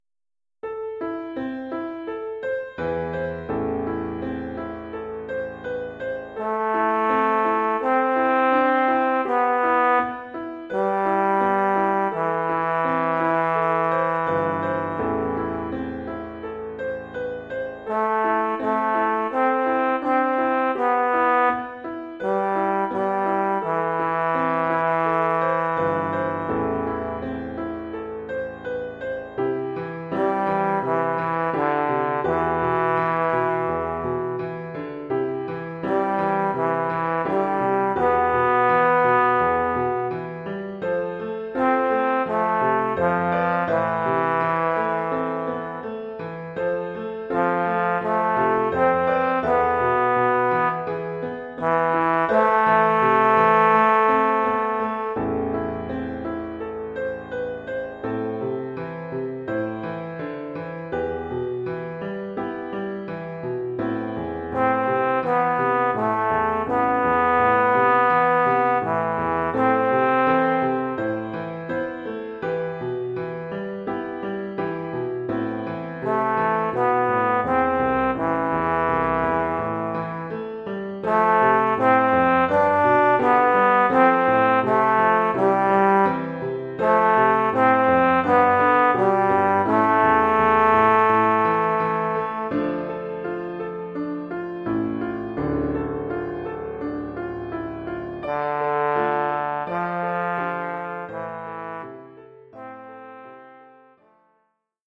Formule instrumentale : Trombone et piano
Oeuvre pour trombone et piano.